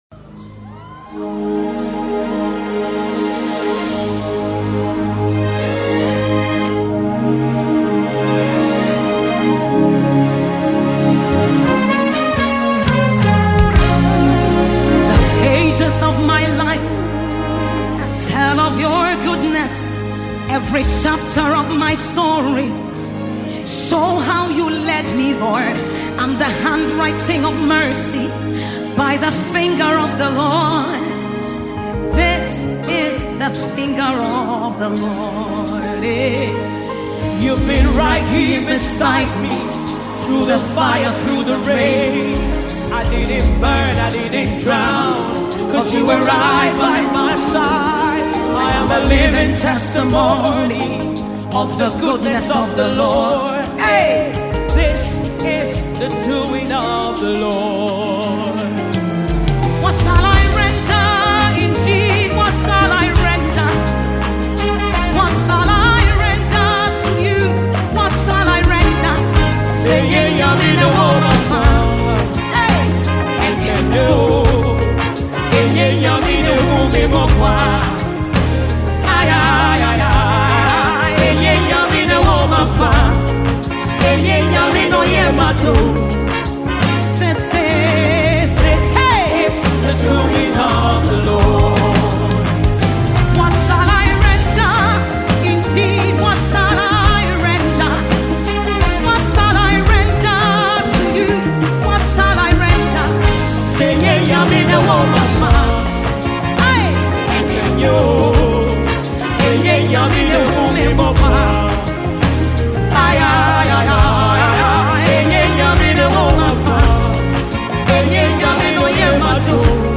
a Ghanaian gospel artist